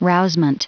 Prononciation du mot rousement en anglais (fichier audio)
Prononciation du mot : rousement